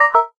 bad_move_01.ogg